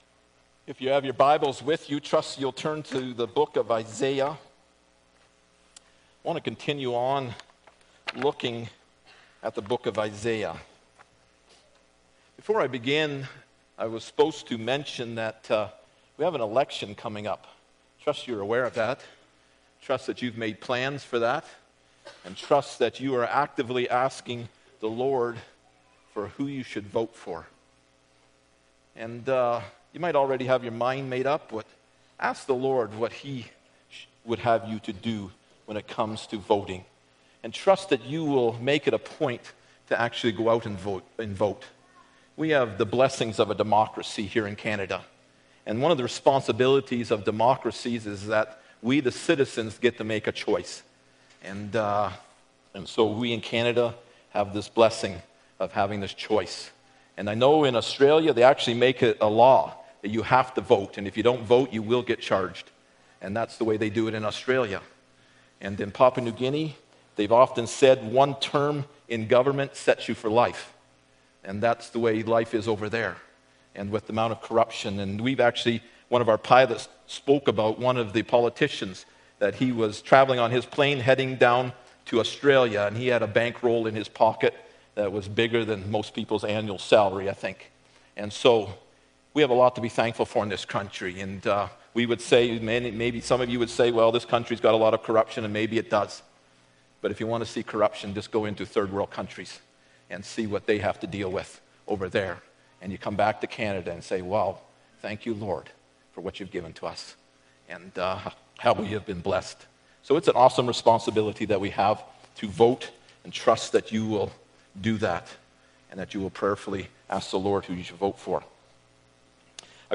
Isaiah 2 Service Type: Sunday Morning Bible Text